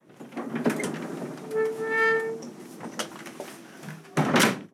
Subir un mueble cama
bisagra
chasquido
chirrido
rechinar
Sonidos: Hogar